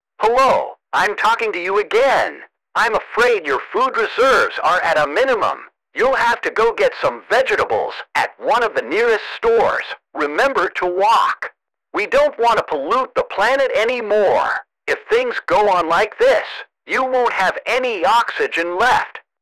To make the game speak in the tasks, as I wanted to include a quite real voice, I had no choice but to create several text-to-speech audio files with an online app and include them in app inventor.